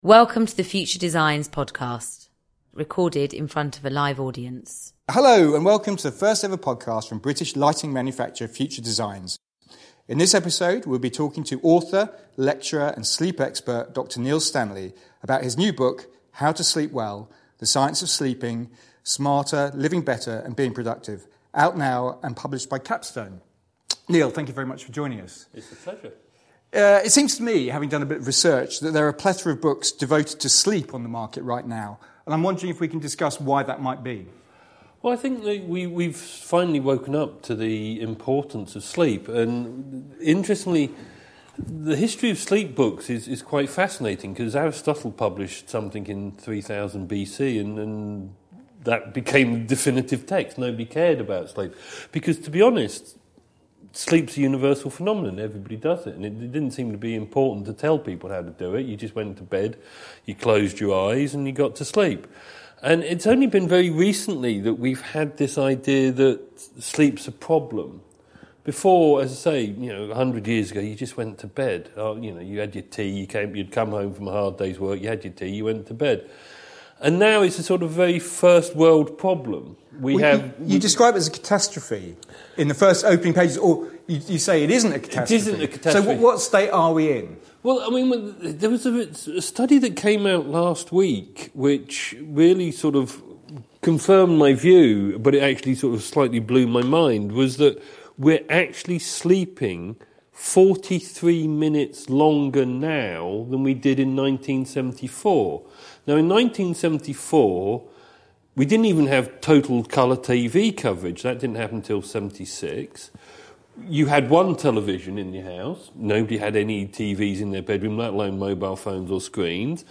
The discussion was recorded in front of a live audience and is now available as a podcast.